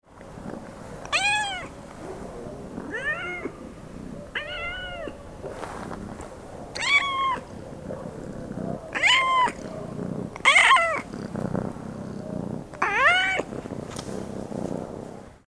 Street Cat Purring And Meowing Sound Effect
Authentic street cat sound effect featuring gentle purring and meowing, perfect for videos, games, and ambient projects.
Street-cat-purring-and-meowing-sound-effect.mp3